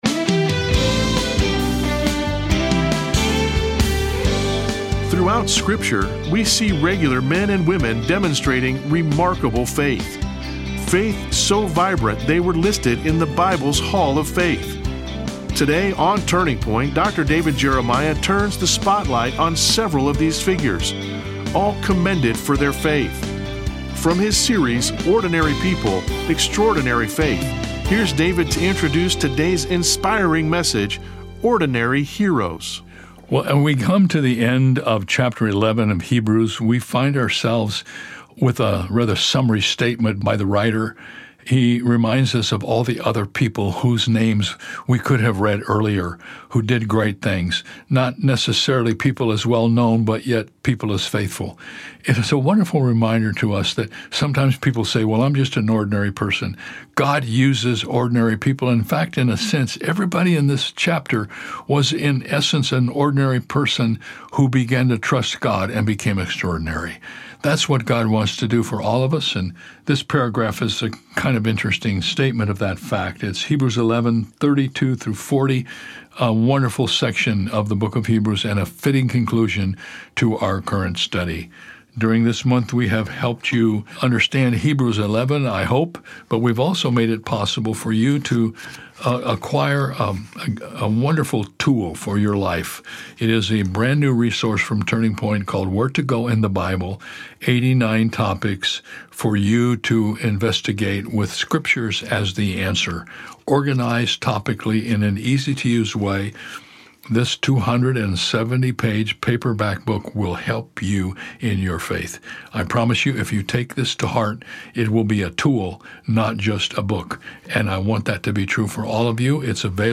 The Old Testament is filled with heroes that subdued kingdoms as they walked in faith believing God. Learn more about Gideon, Barak, and more in this message from Hebrews 11.